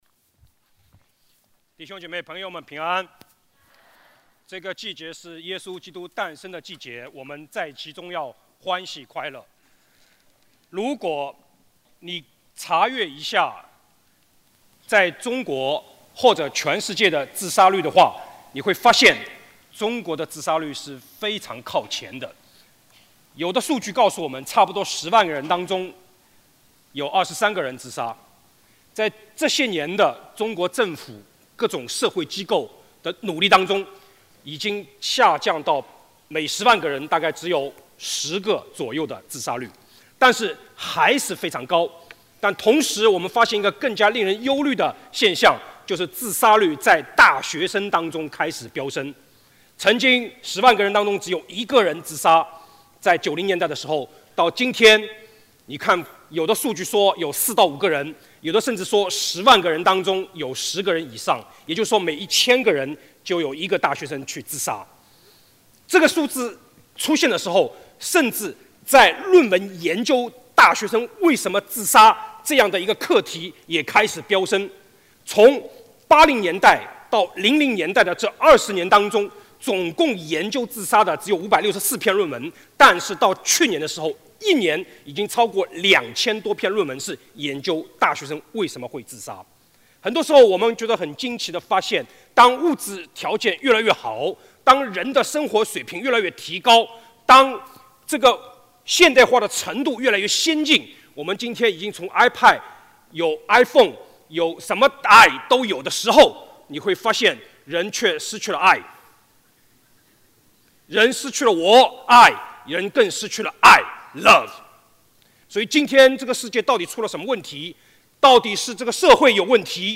主日证道